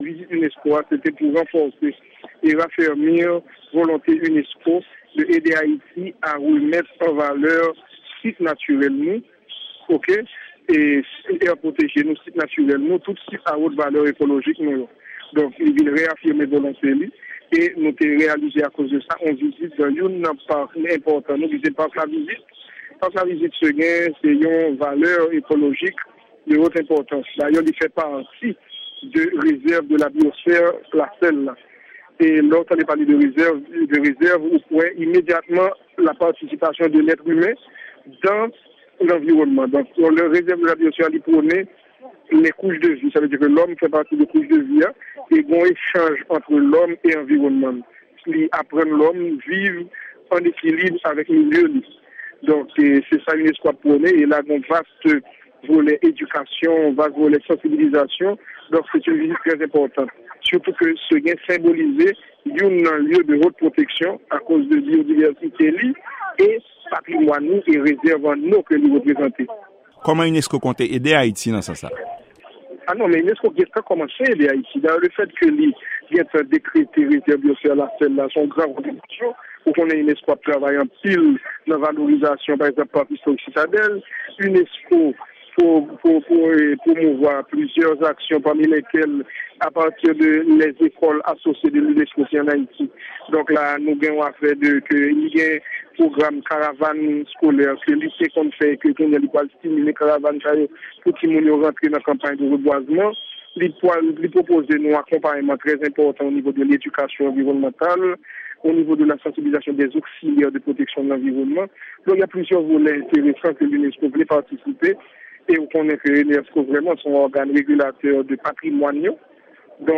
yon entèvou